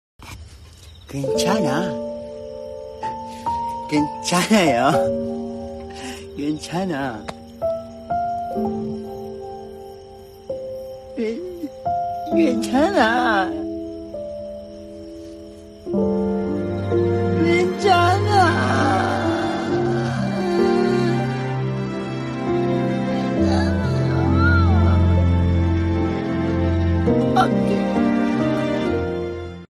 เสียงเอฟเฟคGwaenchanha/ เสียงคินชานา เน่งๆๆๆๆๆ
คำอธิบาย: ดาวน์โหลดเอฟเฟกต์เสียง ไม่เป็นไร เราโอเค, เสียง Gwenchana, เสียง Gwaenchanha, เสียงคินชานา เน่งๆๆๆๆๆ, เสียงคินจานา, download gwenchana sound effect, gwaenchanayo mp3 นี่คือเสียงมีมที่กำลังมาแรงบน TikTok เพื่อใช้ในการพากย์วิดีโอตลกของคุณ
gwenchana-sound-effect-th-www_tiengdong_com.mp3